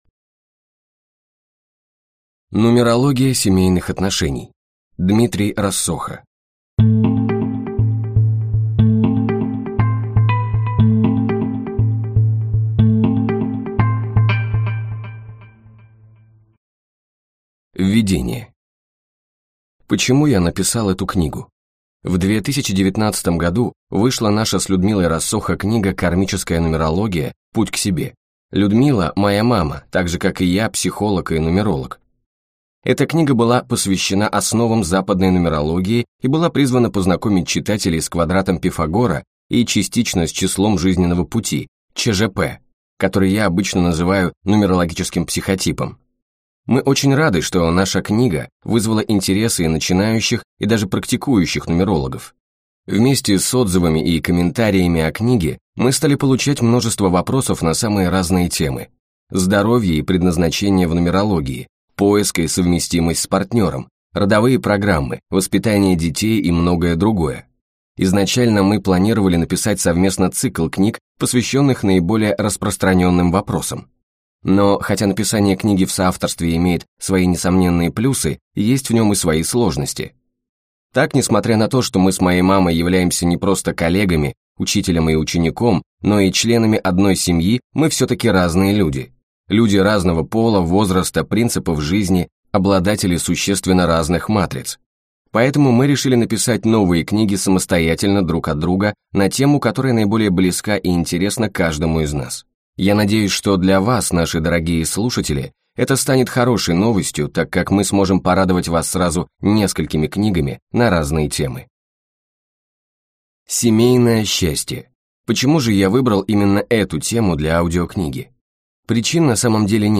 Аудиокнига Нумерология семейных отношений | Библиотека аудиокниг